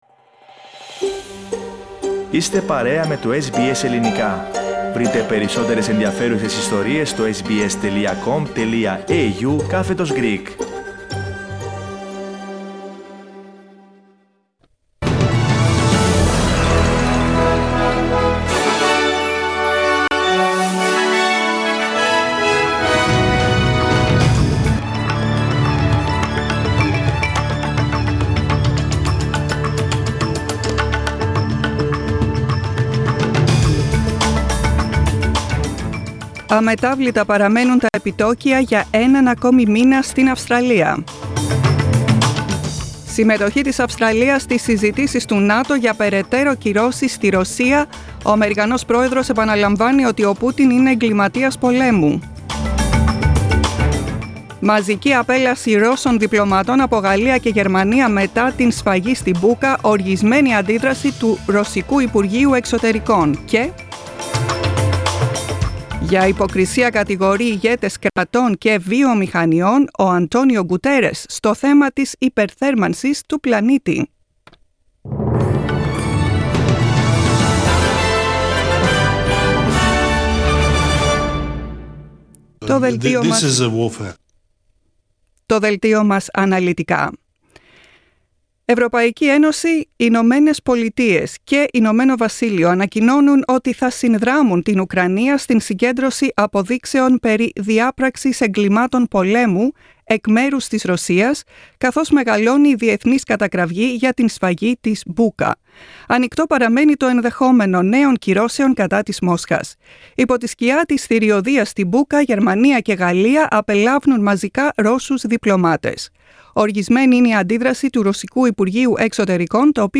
Δελτίο ειδήσεων, Τρίτη 5.4.2022